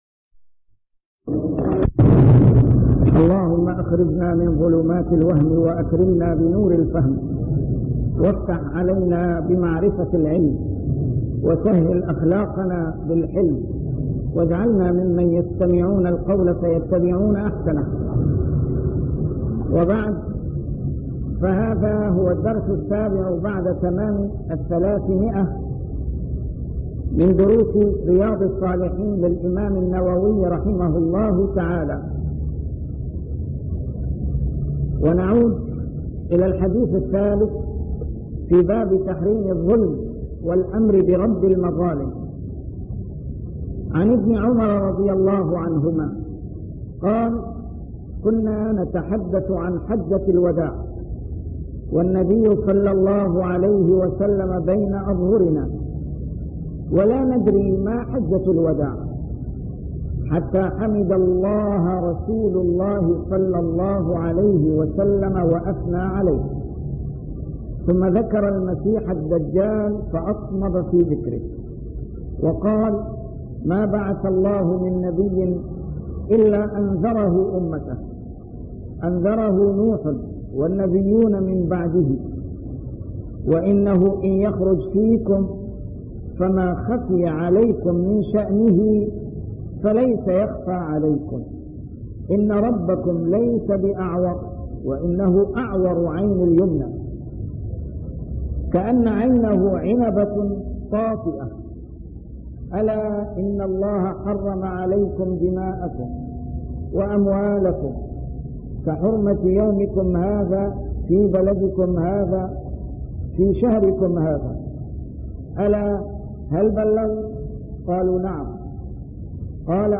A MARTYR SCHOLAR: IMAM MUHAMMAD SAEED RAMADAN AL-BOUTI - الدروس العلمية - شرح كتاب رياض الصالحين - 307- شرح رياض الصالحين: تحريم الظلم